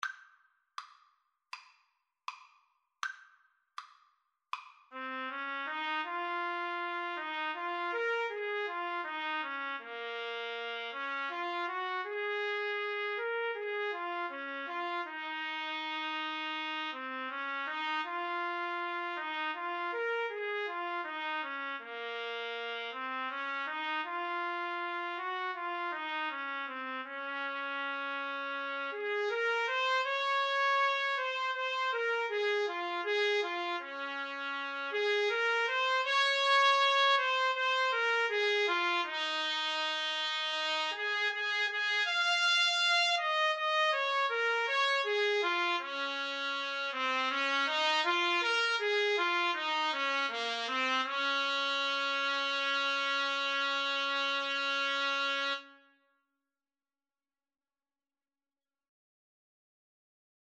Play (or use space bar on your keyboard) Pause Music Playalong - Player 1 Accompaniment reset tempo print settings full screen
Db major (Sounding Pitch) Eb major (Trumpet in Bb) (View more Db major Music for Trumpet Duet )
Espressivo Andante